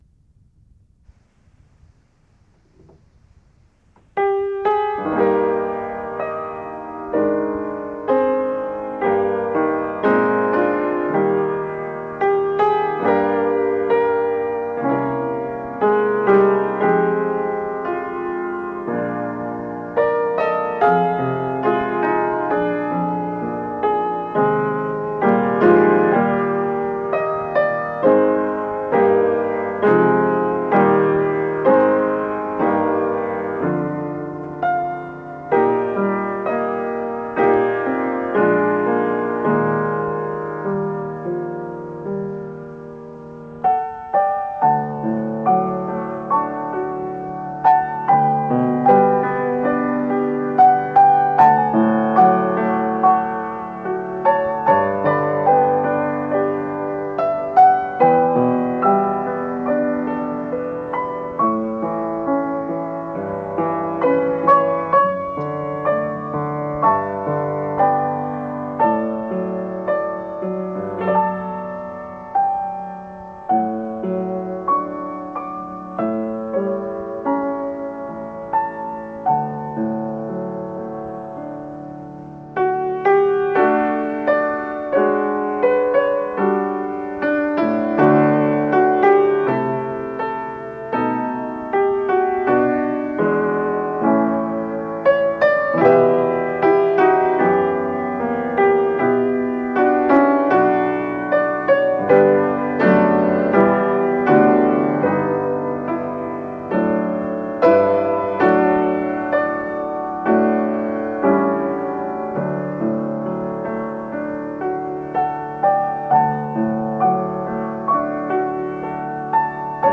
Here are some of my favorite piano arrangements: O Come All Ye Faithful, Silent Night, While Shepherds Watched Their Flocks, Three Carols, Little Drummer Boy